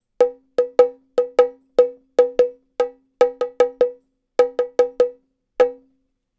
Bongo Fill 05.wav